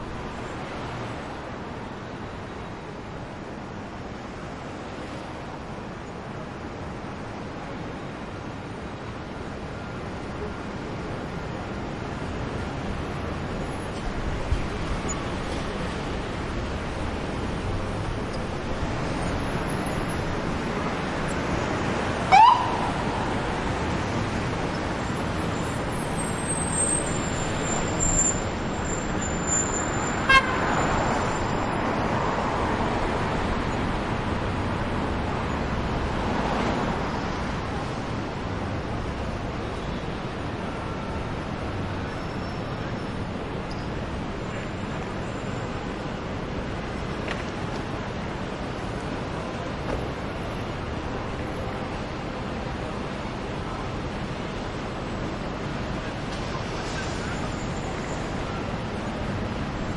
警车警笛声
描述：清晰的警车警笛声。
标签： 警察 汽车 值班 交通 警笛 紧急 警报 车辆
声道单声道